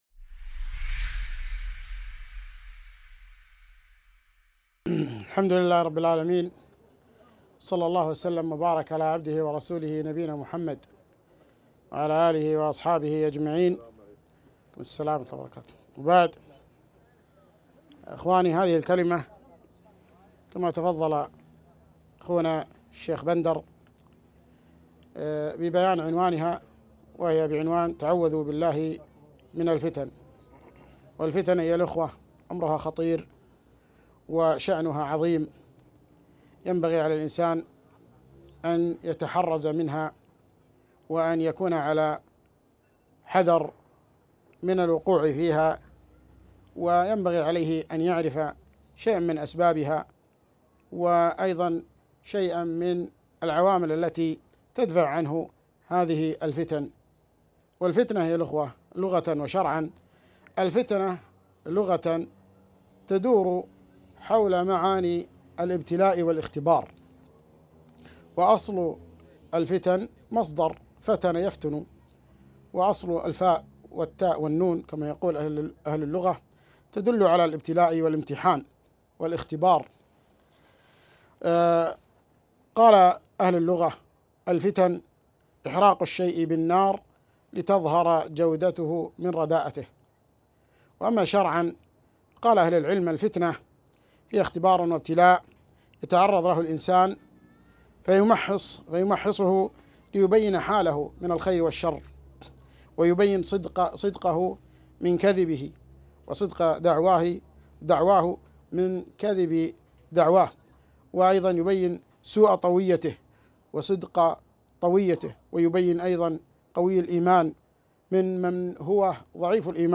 محاضرة - الفتنة وموقف المسلم منها